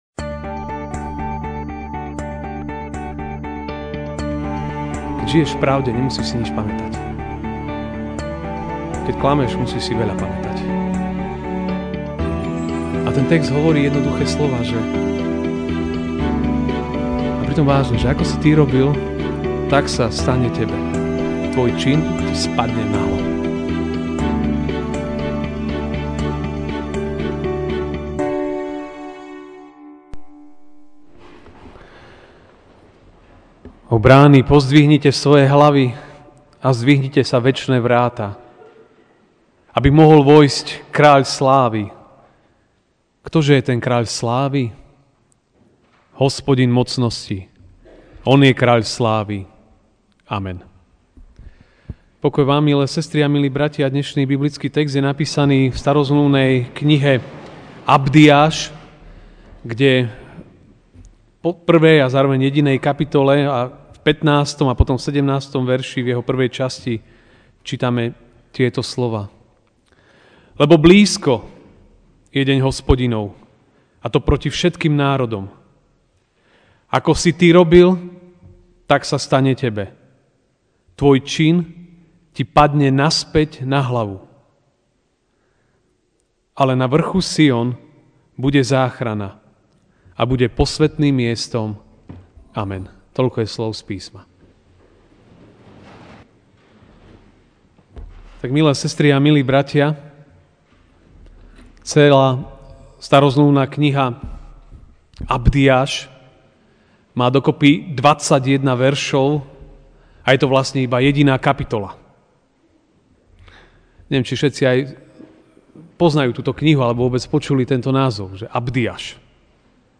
Ranná kázeň: Blízkosť nádeje (Abdiáš 1, 15; 17a)Lebo blízko je deň Hospodinov proti všetkým národom.